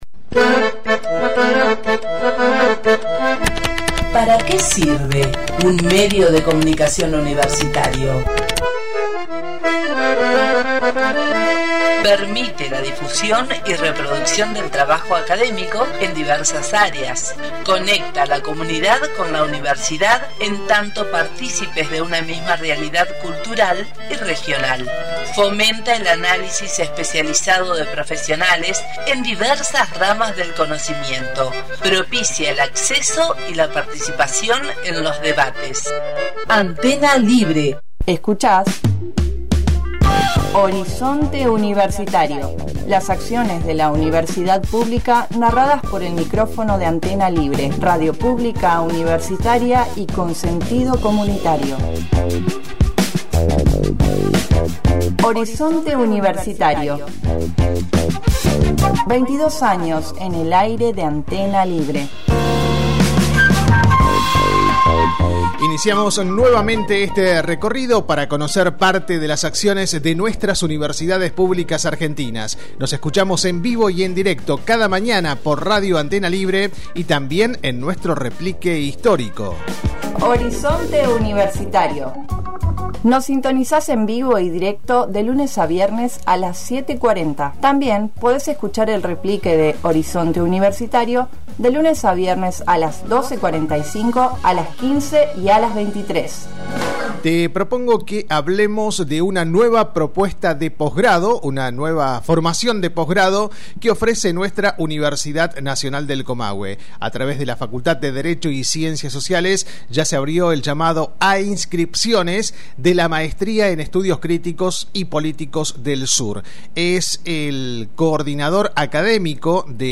dialogó con Horizonte Universitario y brindó detalles de la propuesta: